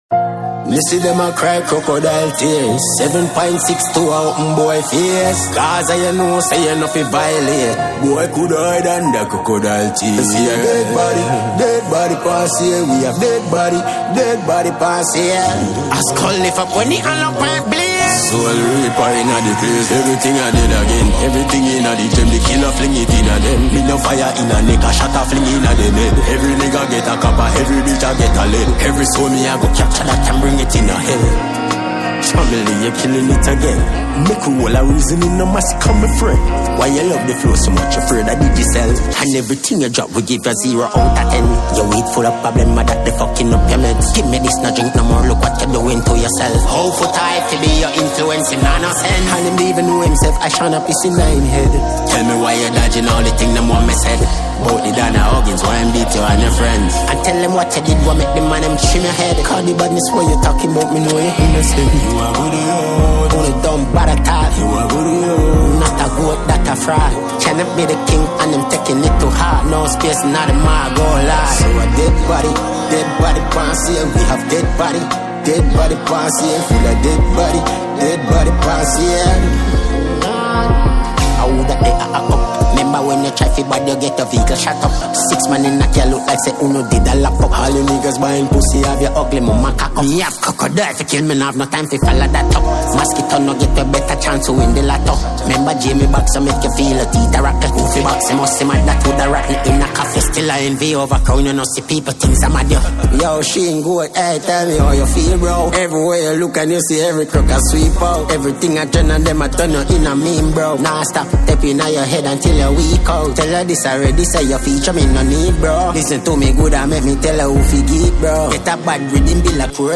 Dancehall
Genre: Dancehall / Conscious